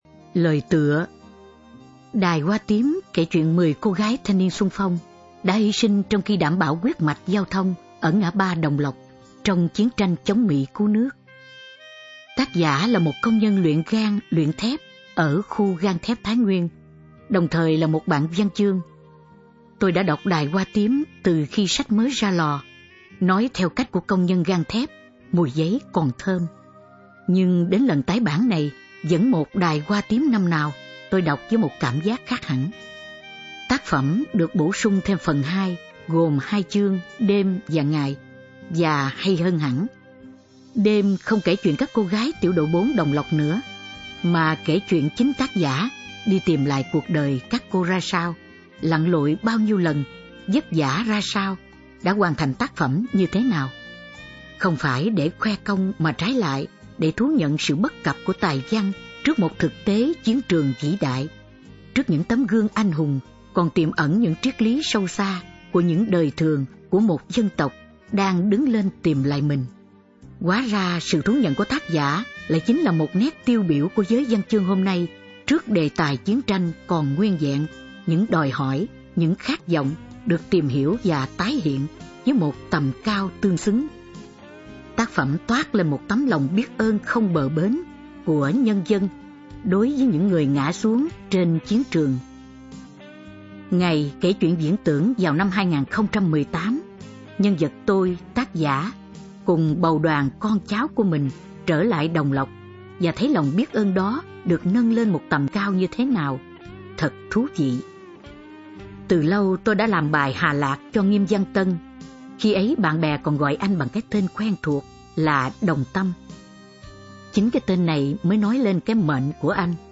Sách nói | Mười cô gái Ngã Ba Đồng Lập